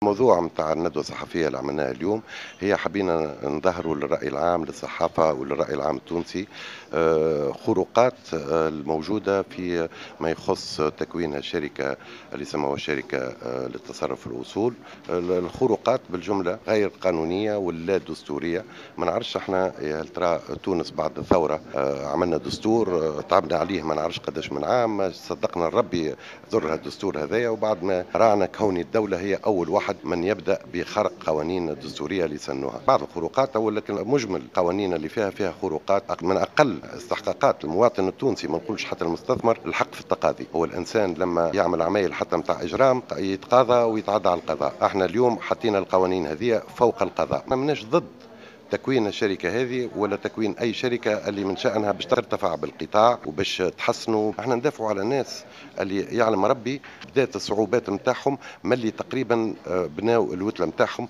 خلال ندوة صحفية عقدوها اليوم الاربعاء 24 سبتمبر 2014